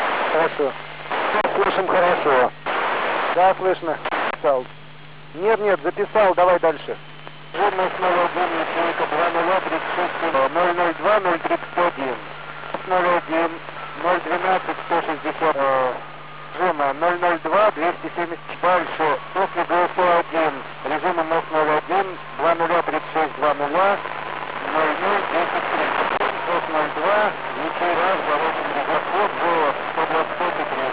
Various recordings from the MIR space station: